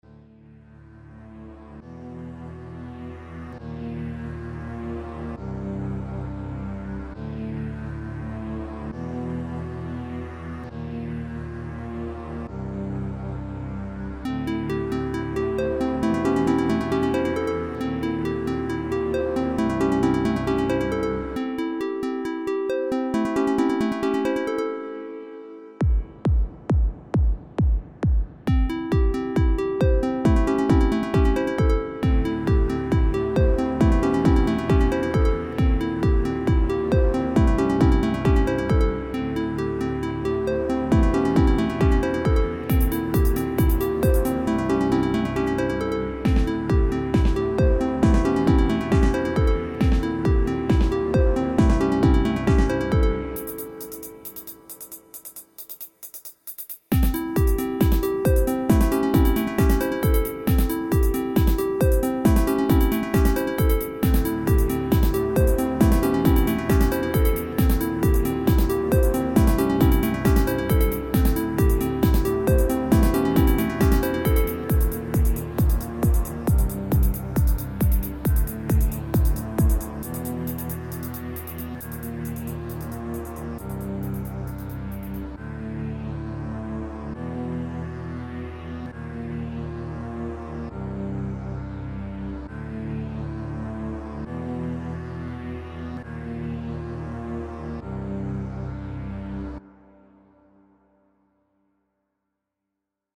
The Darkness - I definitely recommend bringing out your headphones for this song. I finally started experimenting with the mixer component of FL Studio, thus resulting in effects you'll miss if you don't use headphones.